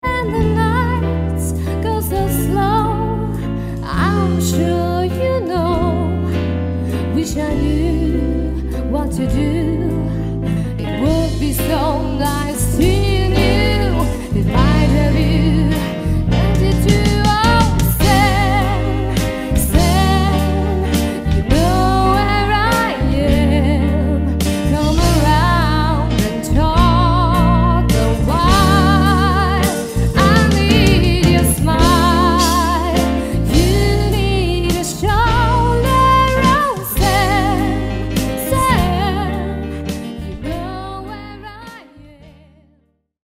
Die Coverband aus Bonn für Ihre Hochzeit.